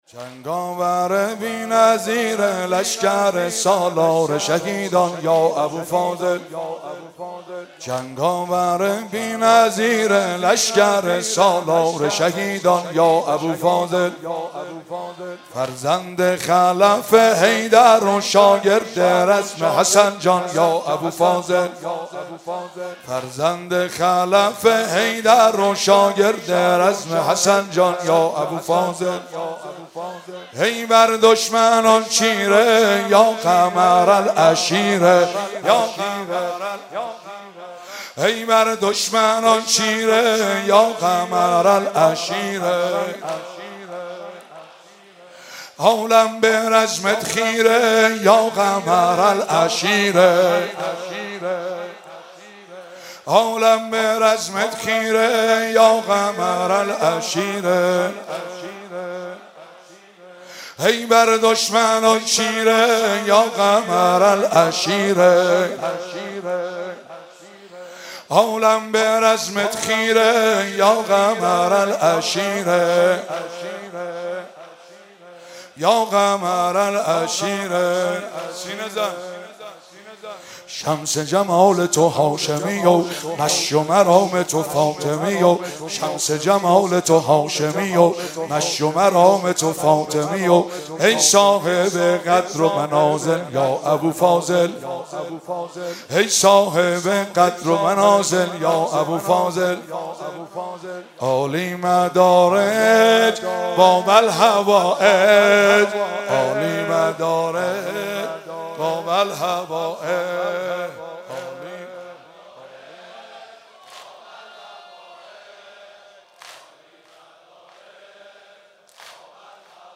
واحد تند شب نهم محرم
با نوای گرم